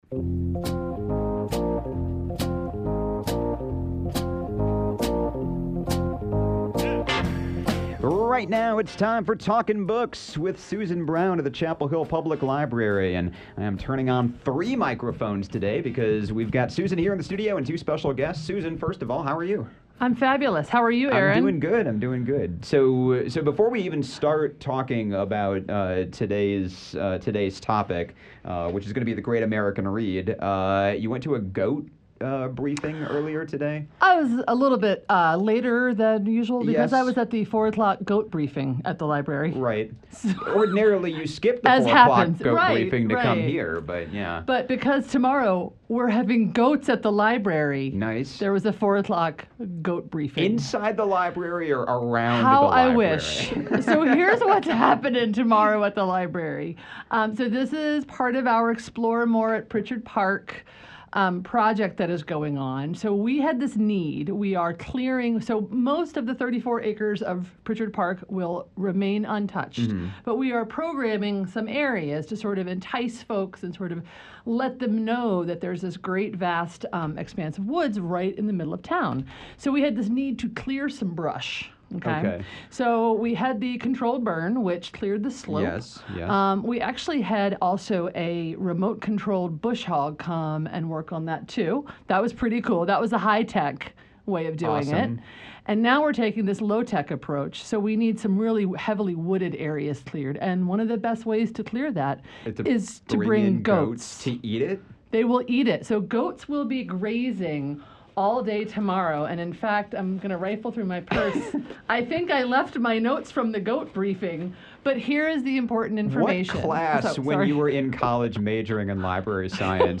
(Bonus: mid-conversation thunderstorm warning!)